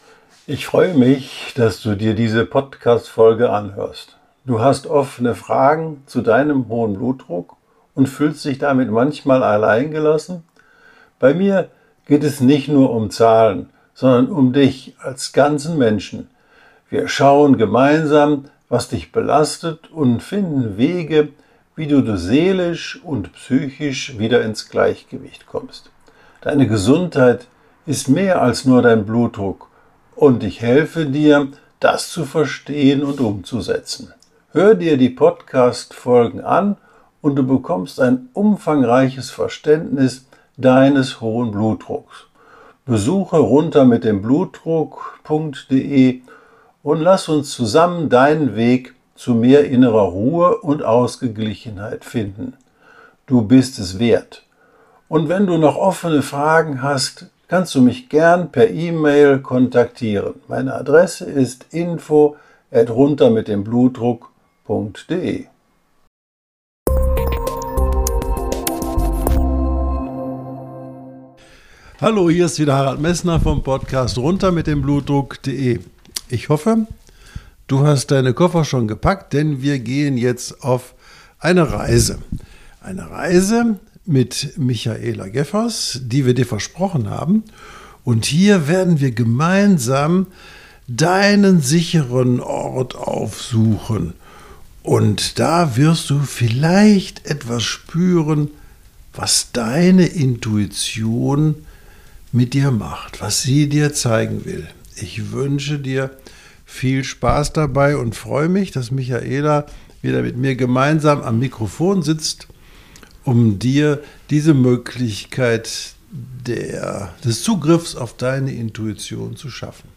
Mit ihrer sanften Anleitung findest du Zugang zu deinen verborgenen Impulsen und lernst, deinen Kopf loszulassen, um dich ganz deinem Bauchgefühl hinzugeben.